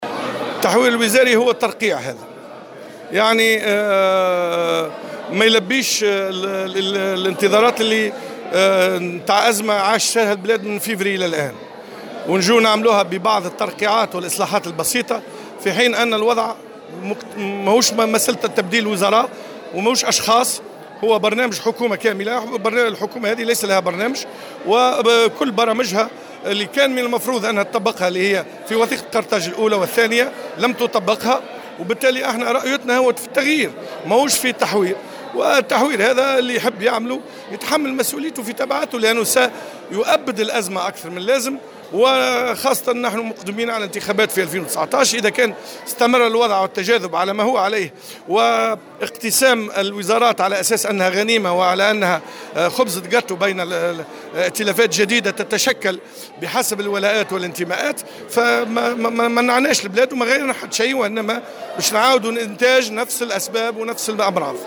في تصريح للجوهرة أف أم، على هامش ندوة إطارات نقابية نظمها الإتحاد الجهوي للشغل بسوسة